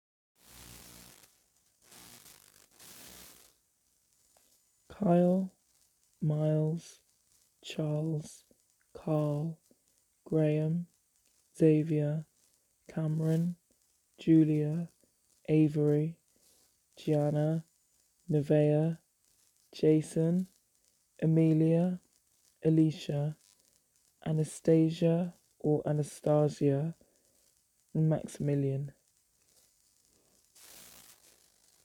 Added a voice recording too, for clarification. And I’m from the UK!
Anastasia- Depends. An-uh-stay-juh is 4 and An-uh-stah-zee-uh is 5